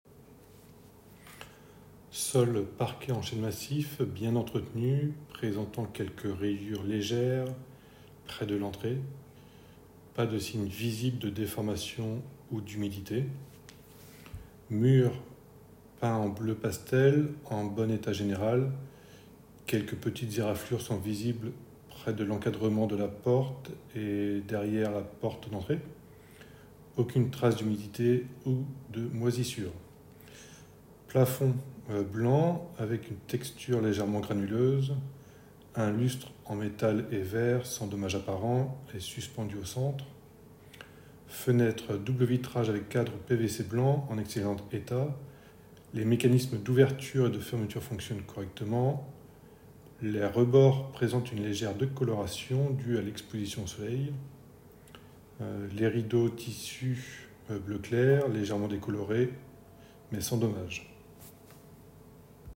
Exemple de retranscription d'un mémo vocal